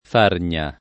fargna [ f # rn’a ]